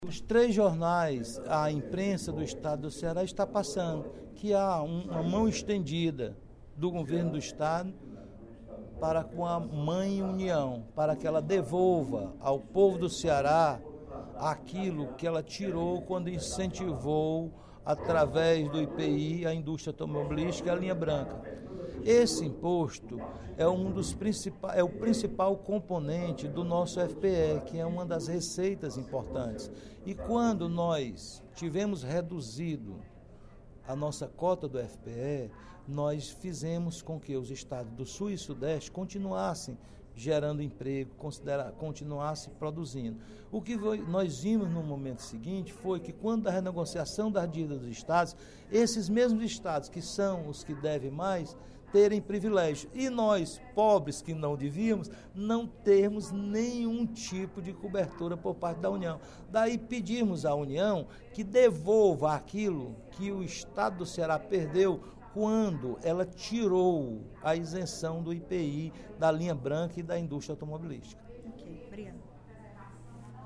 O deputado Roberto Mesquita (PSD) manifestou, nesta sexta-feira (08/07), durante o primeiro expediente da sessão plenária, apoio ao secretário da Fazenda do Estado, Mauro Filho, que está empenhado em recuperar as perdas de repasses da União ao Fundo de Participação dos Estados (FPE).